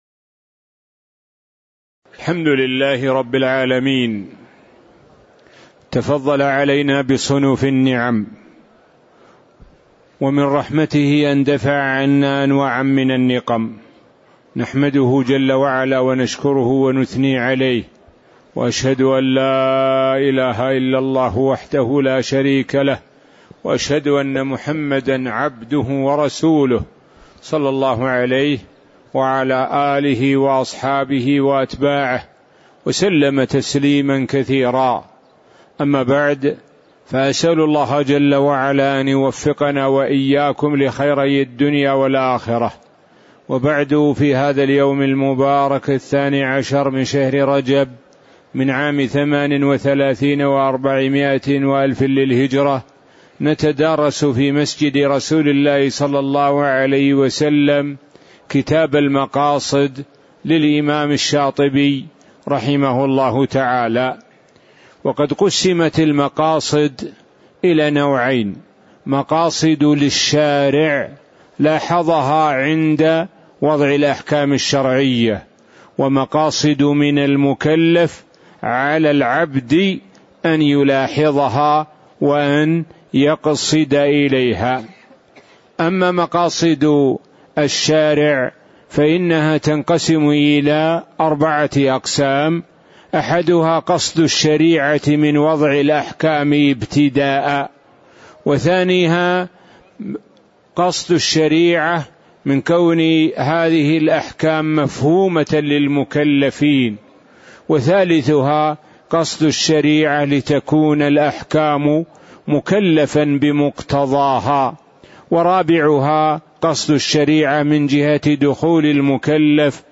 تاريخ النشر ١١ رجب ١٤٣٨ المكان: المسجد النبوي الشيخ: معالي الشيخ د. سعد بن ناصر الشثري معالي الشيخ د. سعد بن ناصر الشثري 01 أٌقسام مقاصد الشرع